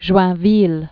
(zhwăɴ-vēl), Jean de 1224?-1317.